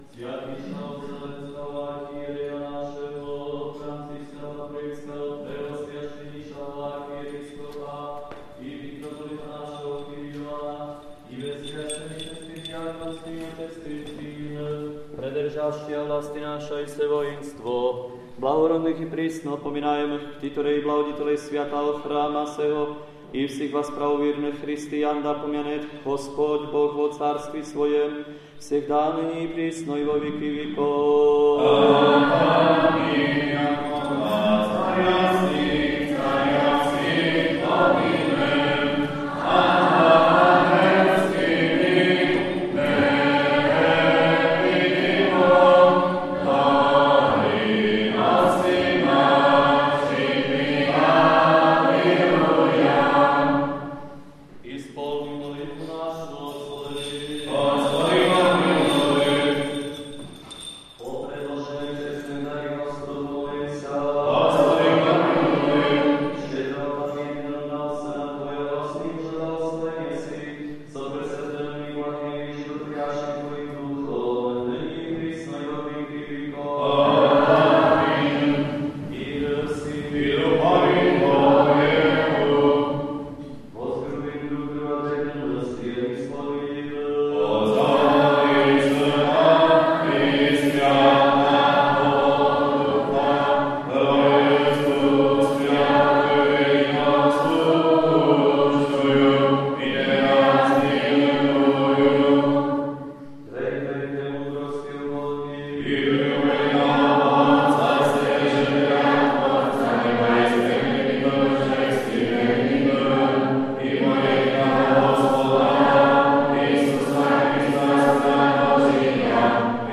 Sv. liturgija14.06.2020 | Obec Kamienka